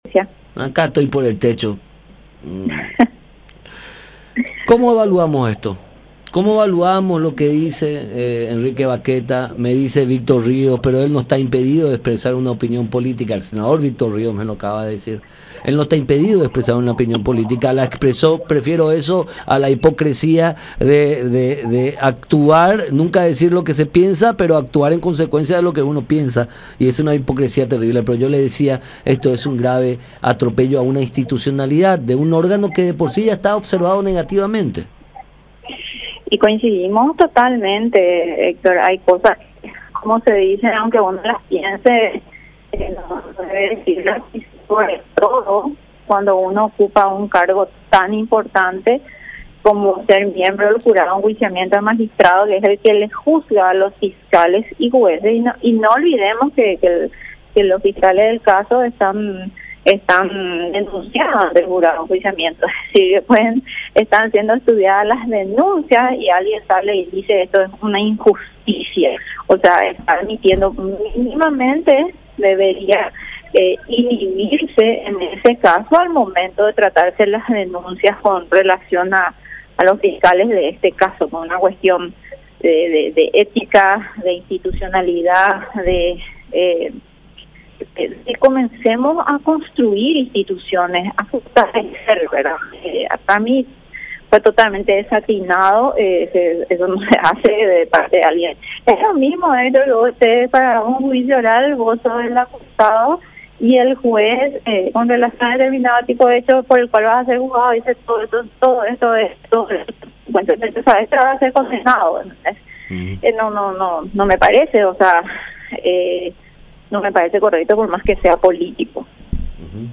La Diputada Nacional, Rocío Vallejos, habló sobre las declaraciones de Enrique Bacchetta, y sostuvo que es una injusticia y que se debe evaluar sobre lo que podría acontecer, ya que es un caso muy delicado “Tenemos que comenzar a construir instituciones, pero creo que las declaraciones fueron desacertadas”, expresó. Vallejos indicó que le pareció incorrecto y además mencionó que se necesita gente que actúe conforme a derecho.